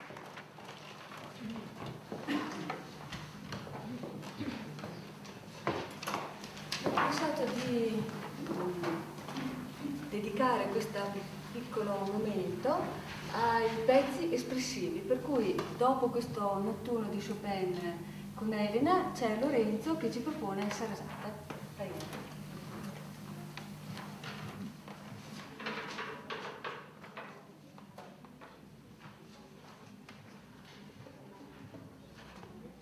I “Mozart Boys&Girls” in concerto - Sabato 21 febbraio 2009